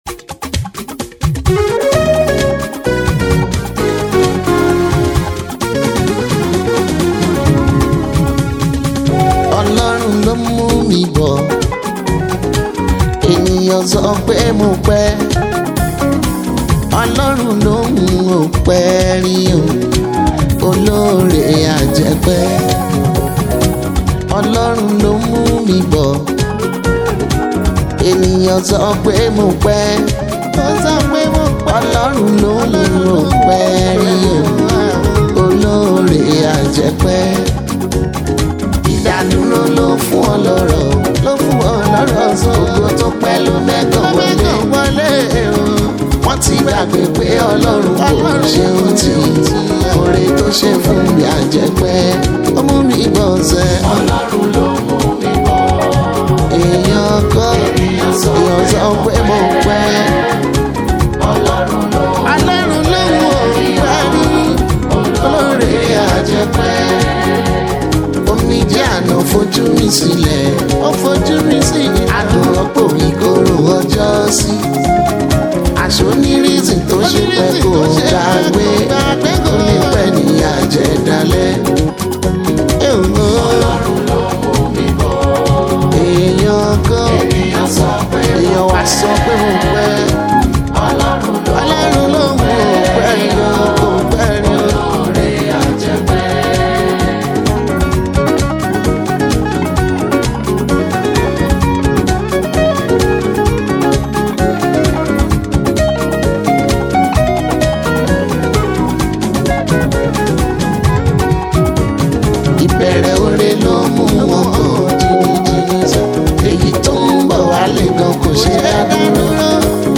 Yoruba Gospel Music
Contemporary Christian music singer